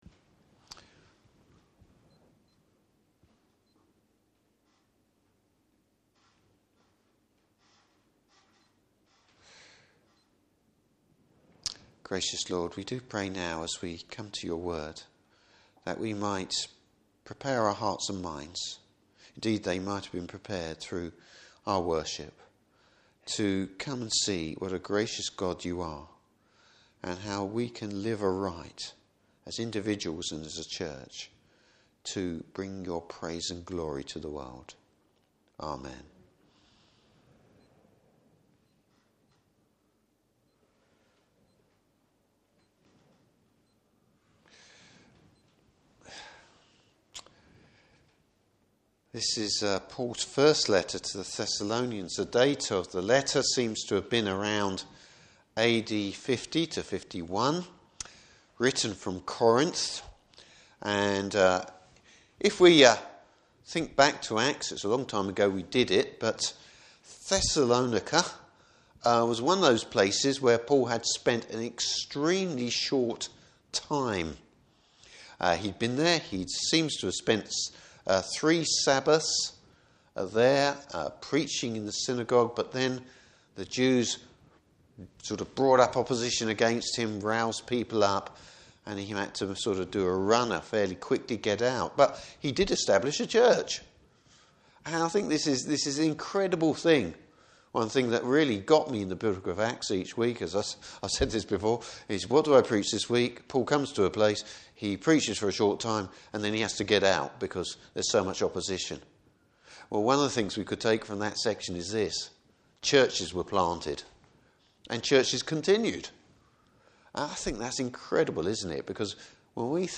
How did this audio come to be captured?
Service Type: Evening Service Bible Text: 1 Thessalonians 5:16-24.